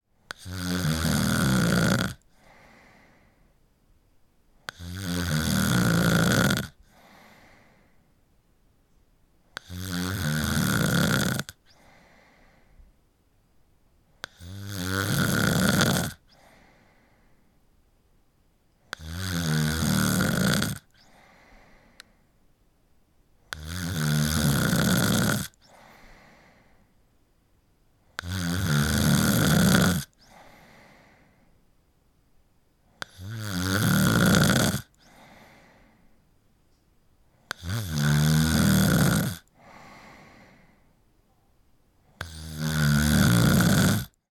Звуки дыхания, храпа
Человек громко храпит